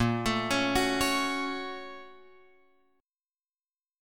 A# Minor Major 7th Sharp 5th